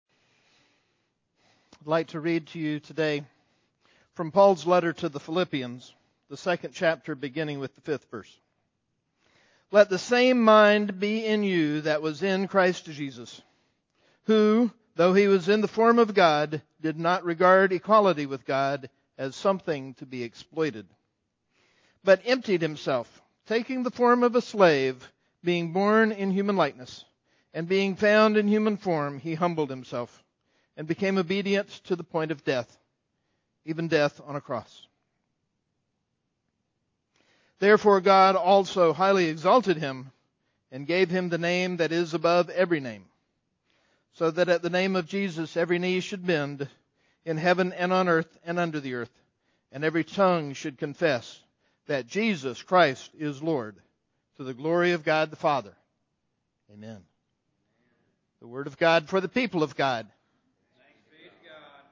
Scripture: Phillipians 2:5-11